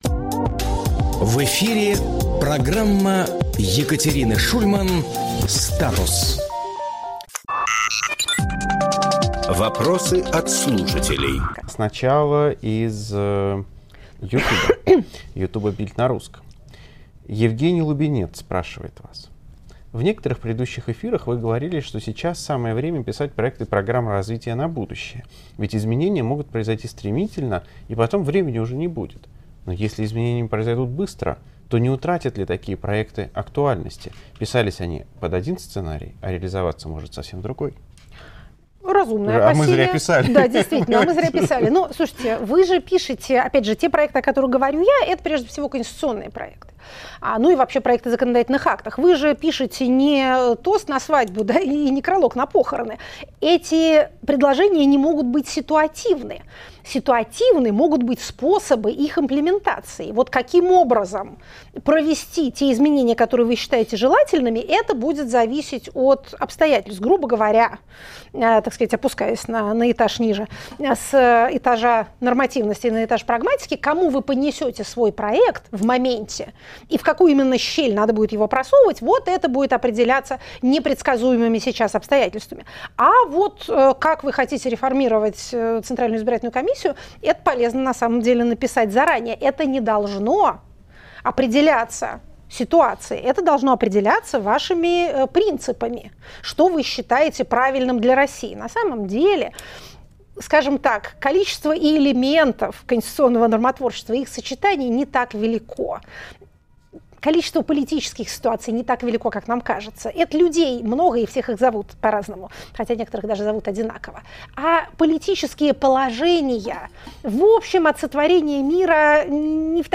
Екатерина Шульманполитолог
Фрагмент эфира от 20.05.25